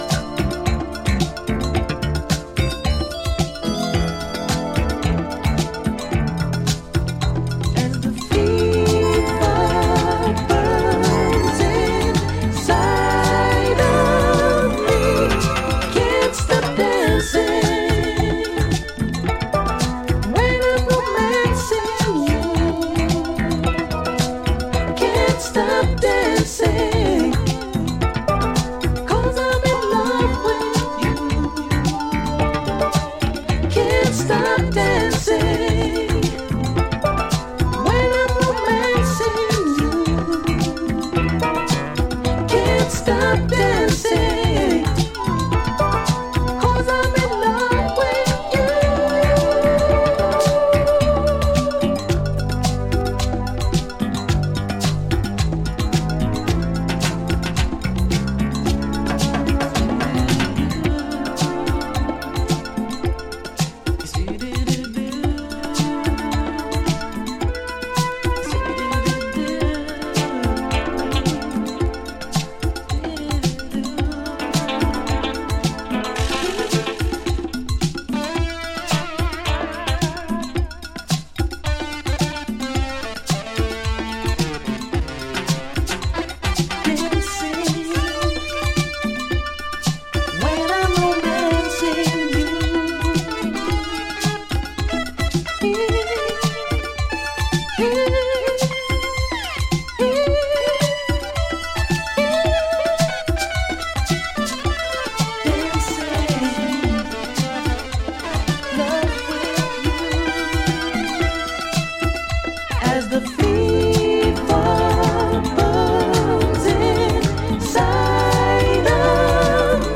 the perfect soundtrack for a mellow summer night